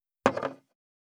211,グラス,コップ,工具,小物,雑貨,コトン,トン,ゴト,ポン,ガシャン,ドスン,ストン,カチ,タン,バタン,スッ,サッ,コン,ペタ,パタ,チョン,コス,カラン,ドン,チャリン,
コップ効果音物を置く